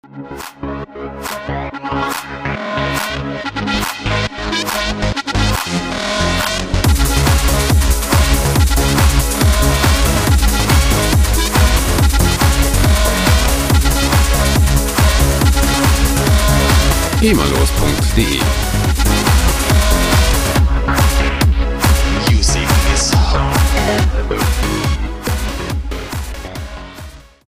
gema-freie Loops aus der Rubrik "Trance"
Musikstil: Tech Trance
Tempo: 140 bpm